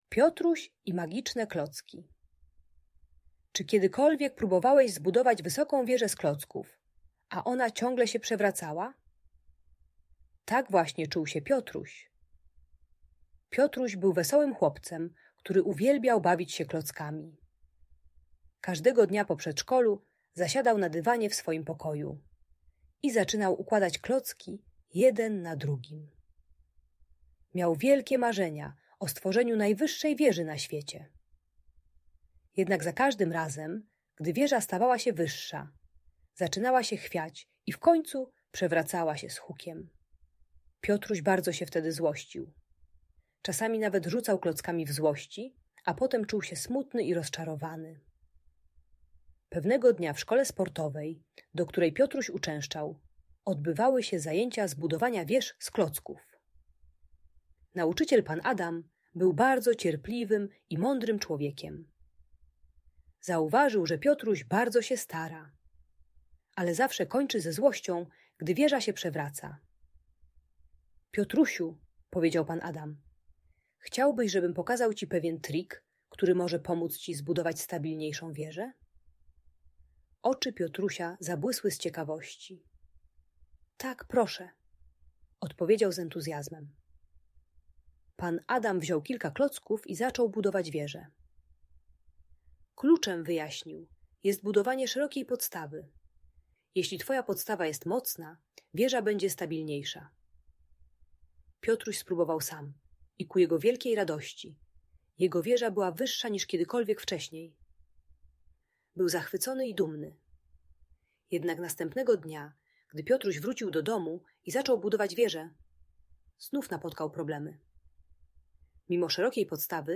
Piotruś i Magiczne Klocki - Audiobajka